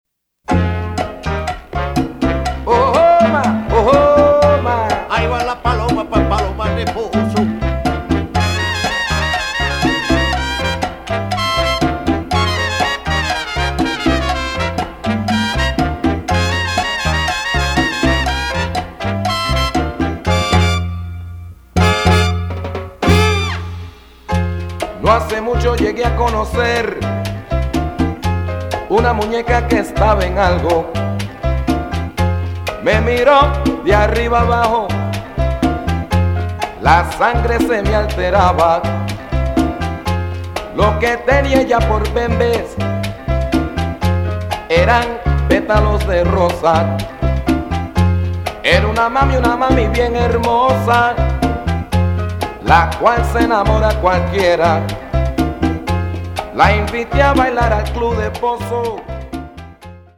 Sample tracks of this Exclusive  Mix CD: